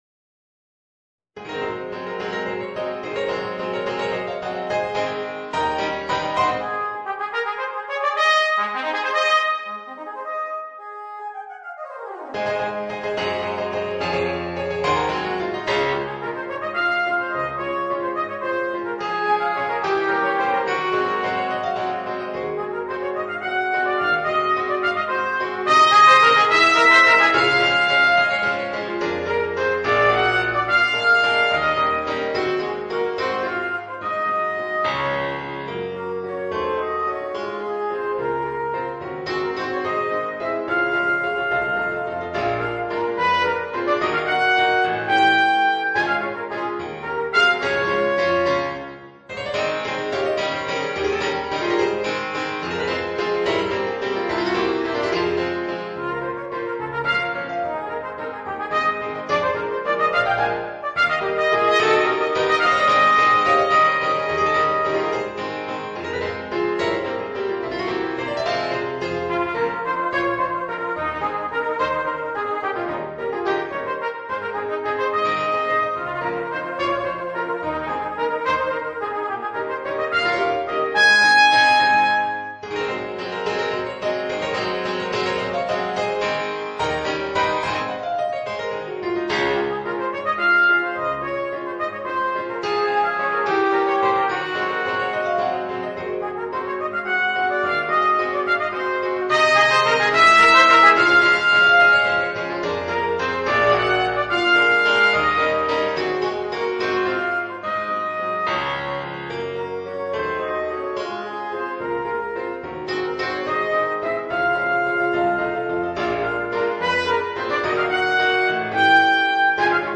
Voicing: Cornet and Piano